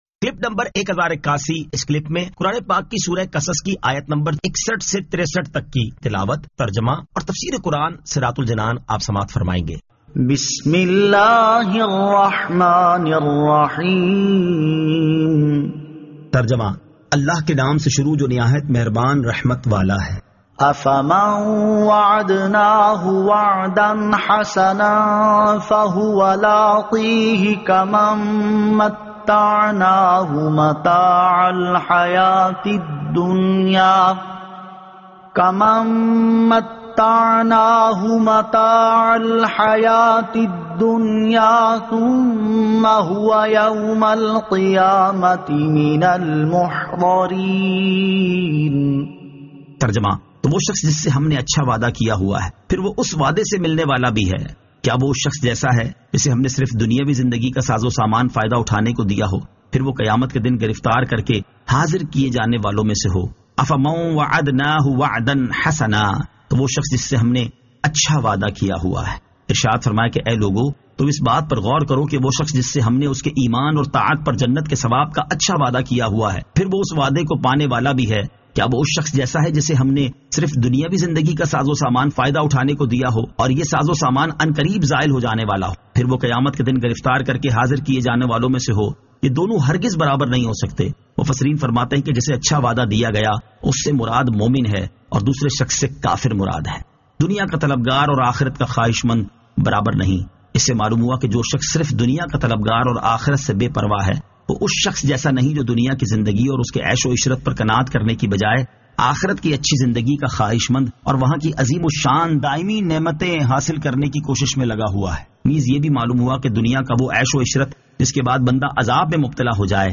Surah Al-Qasas 61 To 63 Tilawat , Tarjama , Tafseer